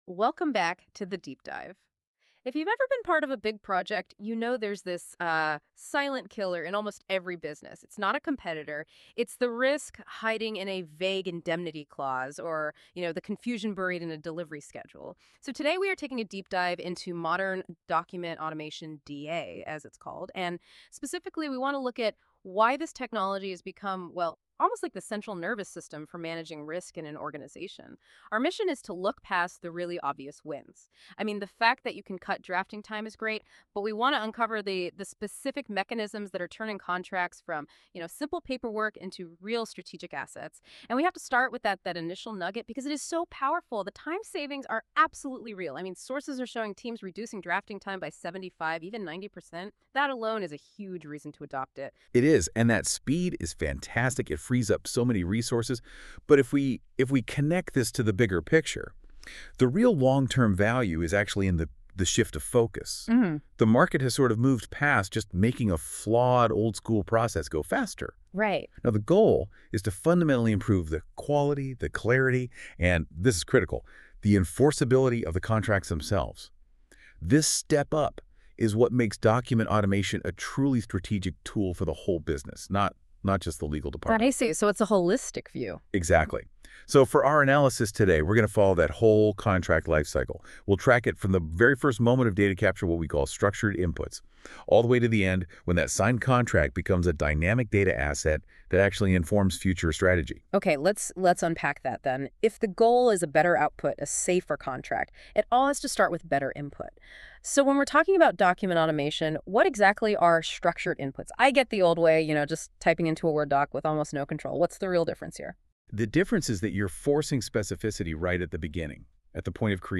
Google Notebook LM -Enhancing Contract Outcomes with Document Automation.m4a